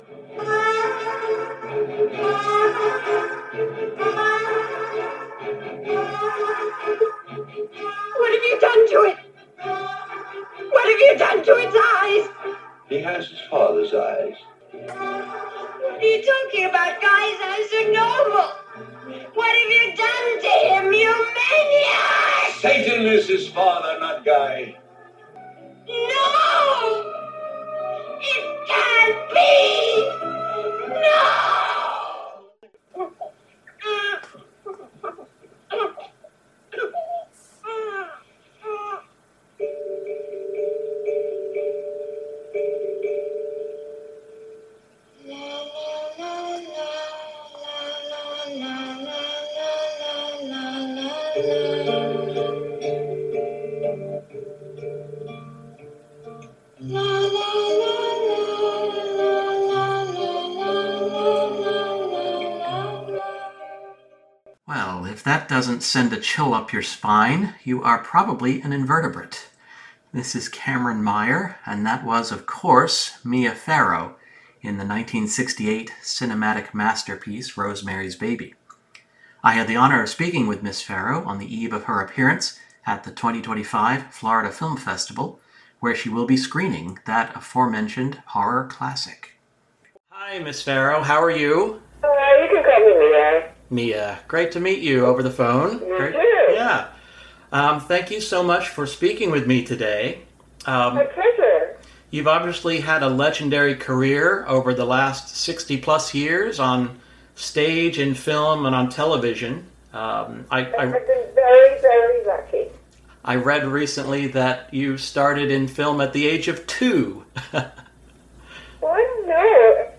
Interviews
Mia-Farrow-interview-2025.mp3